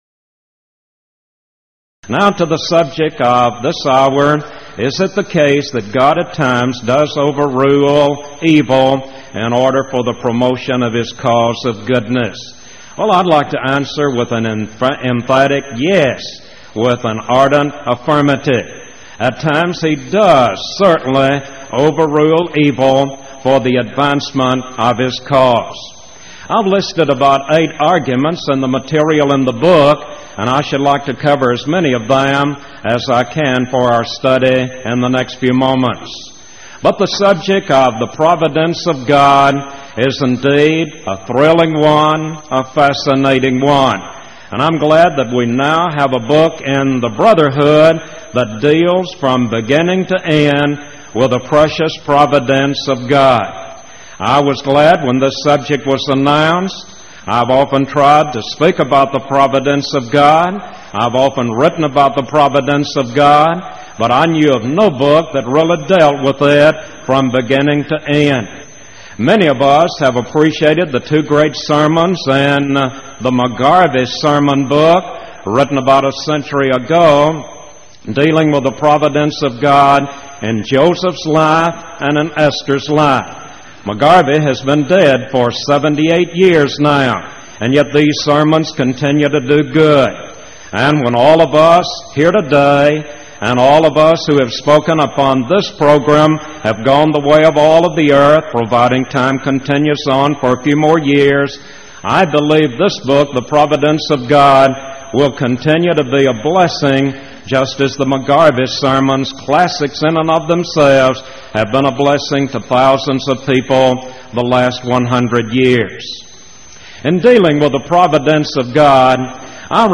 Series: Power Lectures Event: 1989 Power Lectures